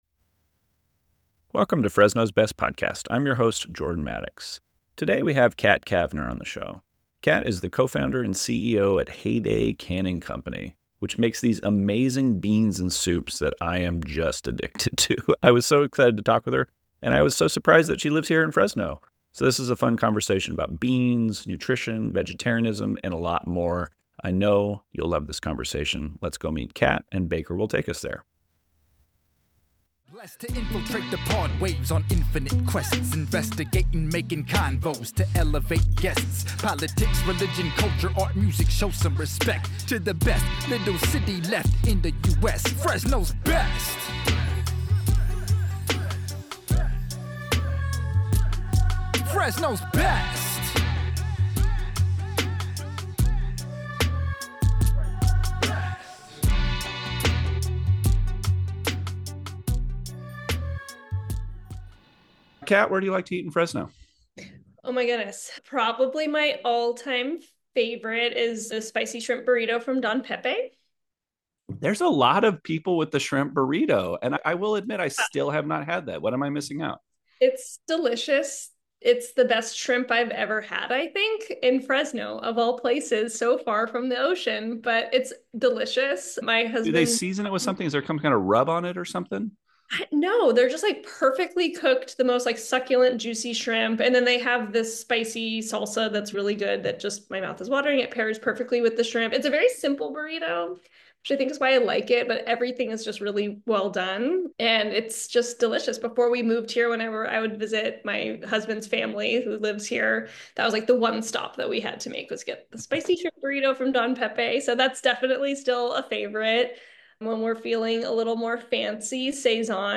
We dig into all things beans in this conversation.